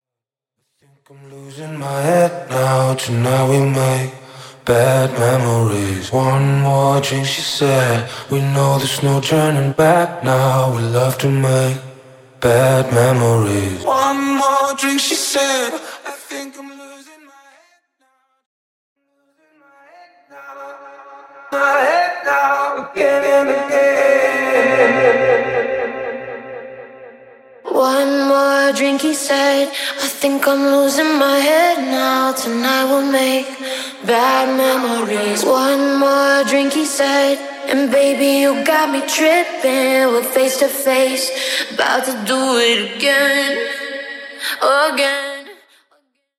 HERE IS A DEMO OF THE FAN MADE STUDIO ACAPELLA VERSION: